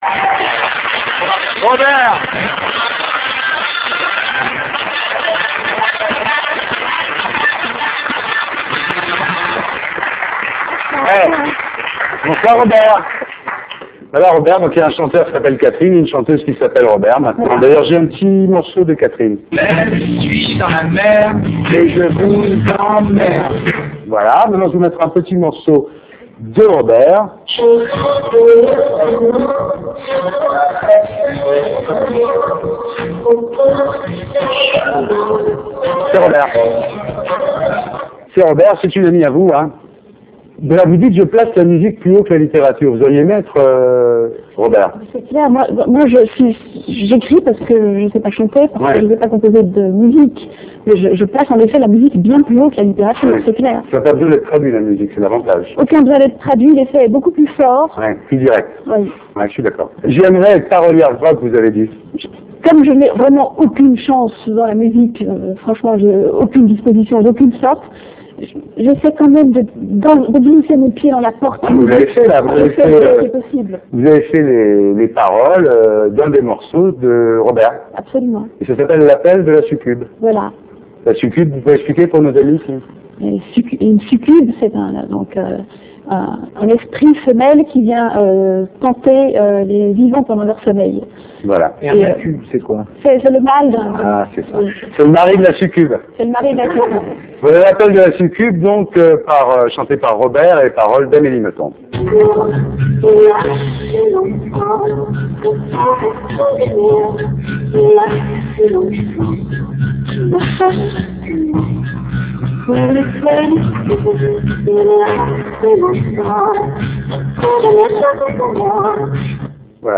Amélie Nothomb et Robert (4'41'') On ressent bien le malaise général et l'ambiance malsaine du plateau. Charlotte de Turckeim et Elie Sémoun s'en donnent à coeur joie et se moquent ouvertement des propos d'Amélie Nothomb...
Les coupures au montage de l'émission semblent avoir été nombreuses.